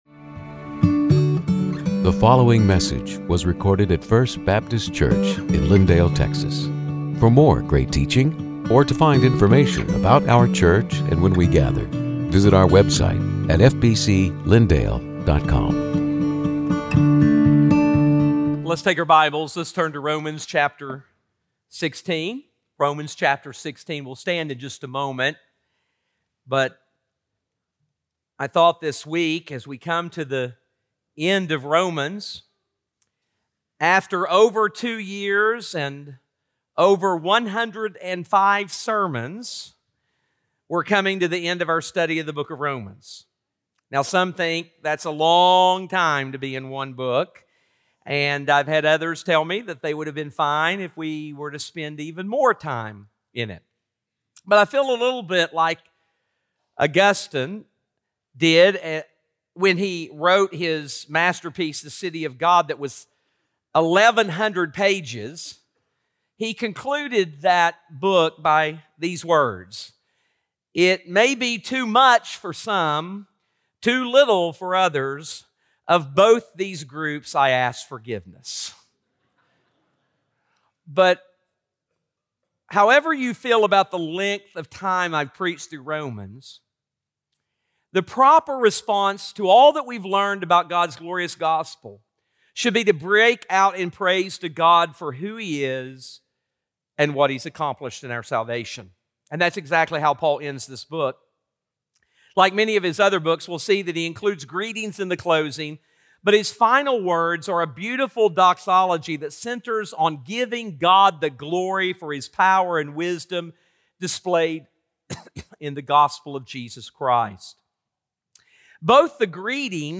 Sermons › Romans 16:21-27